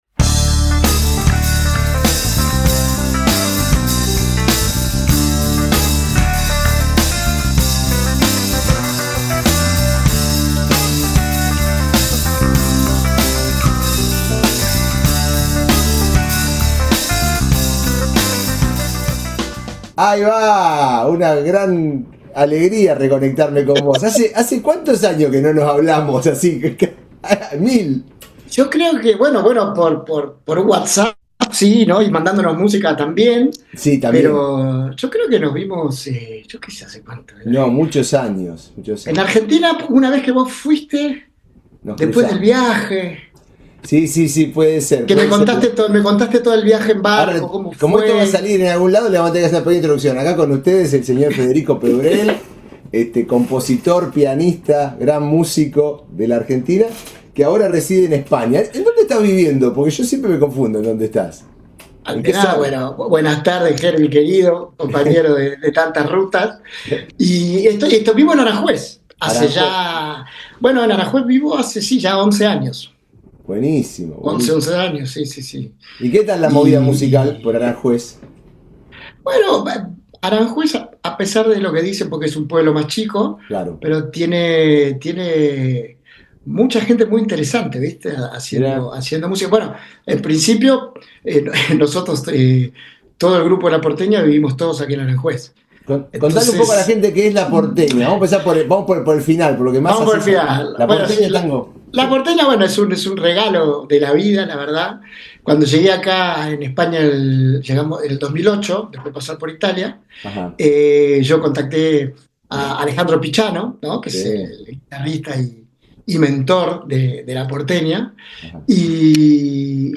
A lo largo de esta charla, hablamos sobre el proceso creativo, la composición como forma de identidad, el rol del piano en distintos géneros y la experiencia de moverse entre proyectos colectivos con estéticas muy diferentes. Una conversación profunda sobre música, canciones y oficio, donde el recorrido personal se cruza con la escena y la búsqueda artística constante.